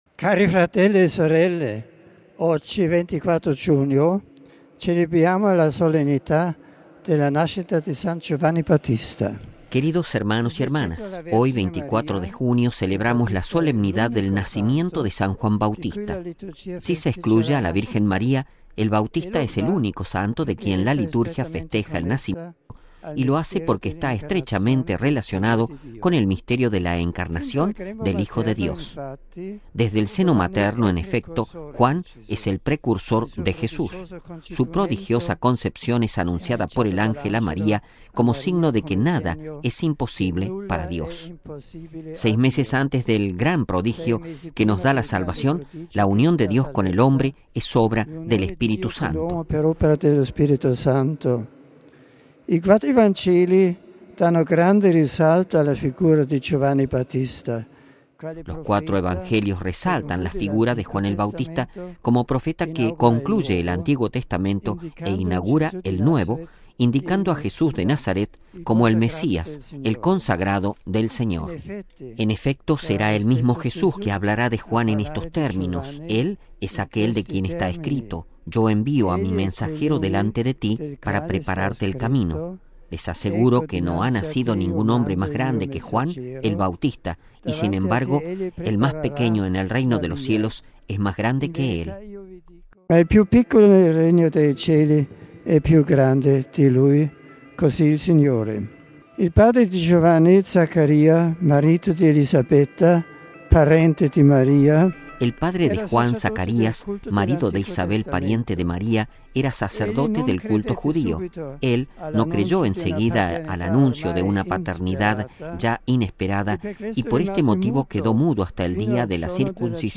Así recordó el Obispo de Roma, en el día de la fiesta del Nacimiento de Juan Bautista, en su reflexión previa a la oración del Ángelus.
Texto y audio completo de la reflexión (audio) RealAudio